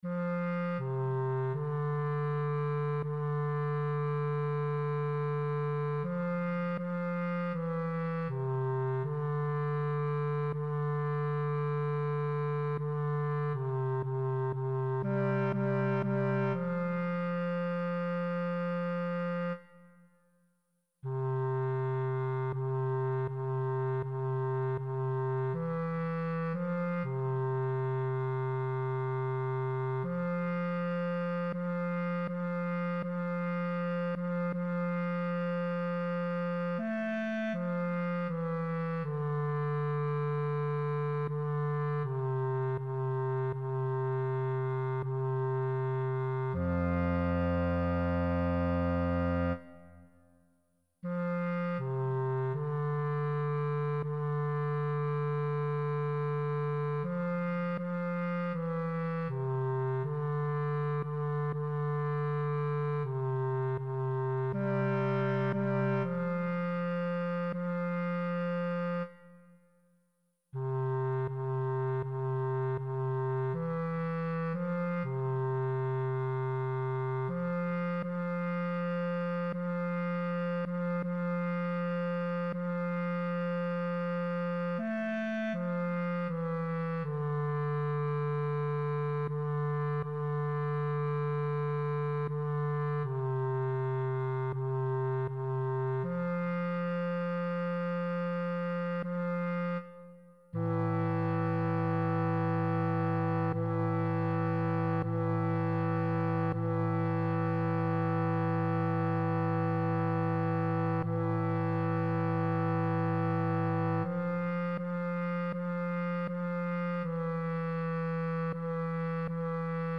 alto, tenor,